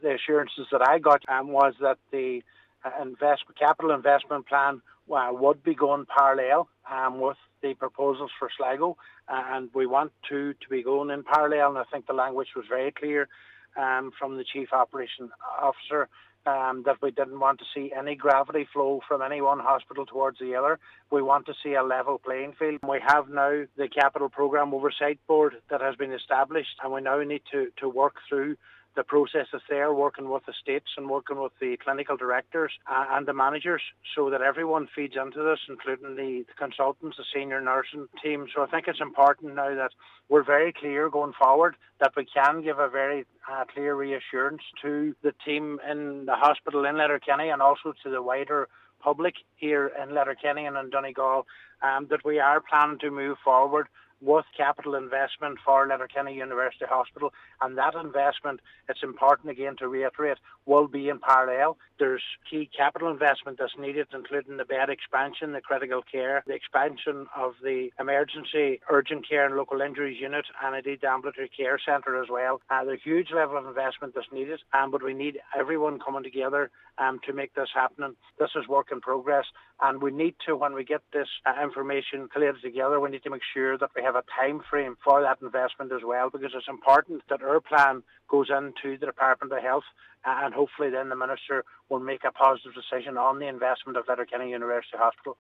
Cllr Brogan says it’s vital all interested parties are united to secure the appropriate investment in Letterkenny University Hospital: